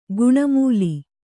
♪ guṇamūli